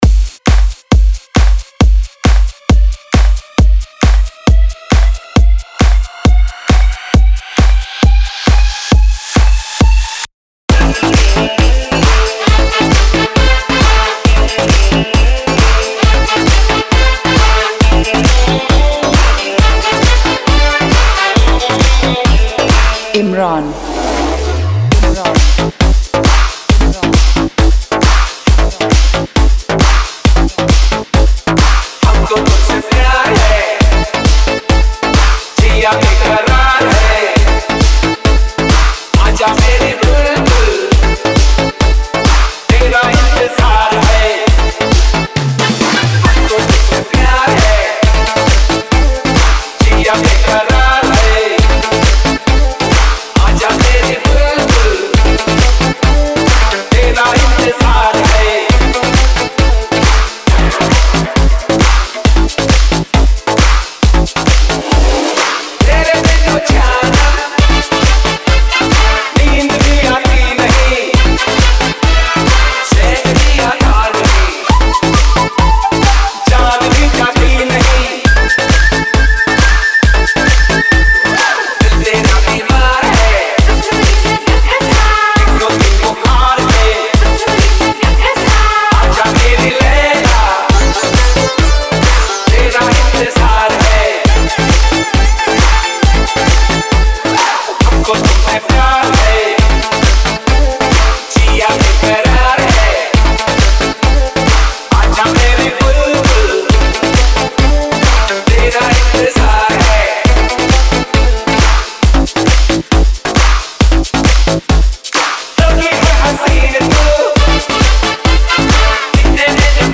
Category : Old Hindi DJ Remix Songs